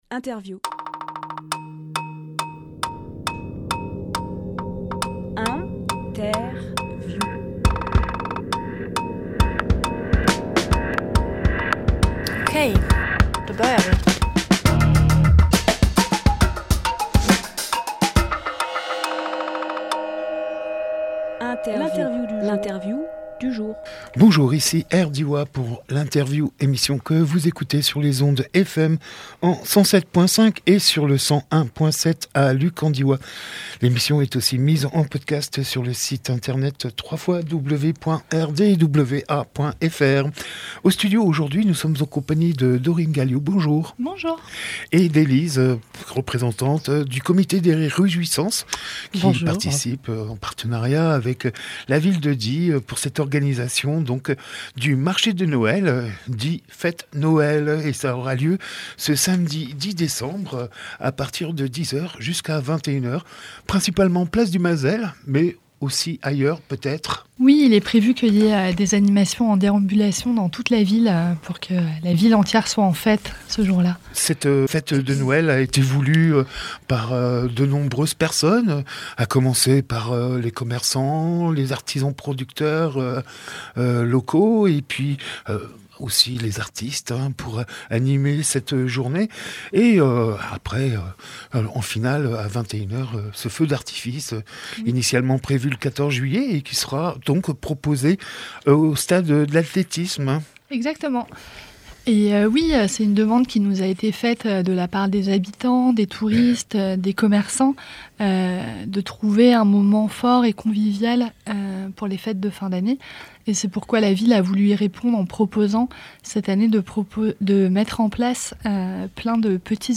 Emission - Interview Die fête Noël Publié le 7 décembre 2022 Partager sur…
07.12.22 Lieu : Studio RDWA Durée